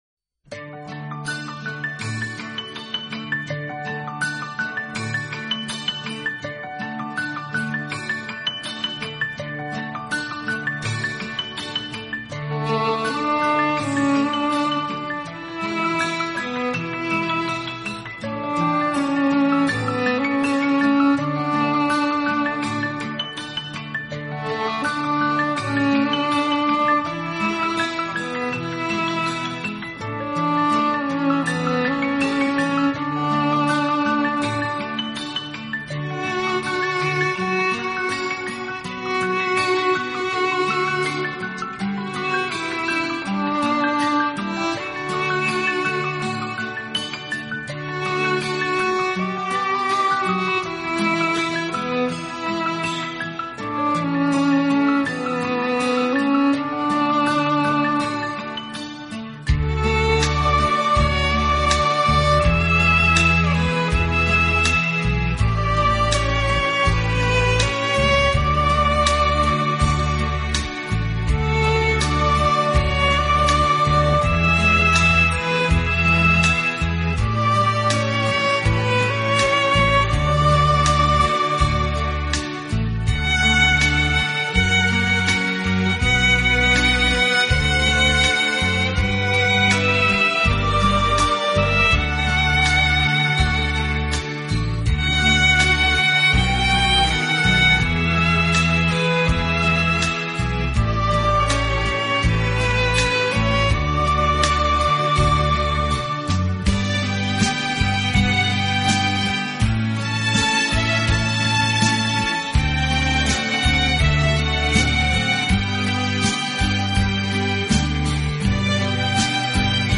【优美小提琴】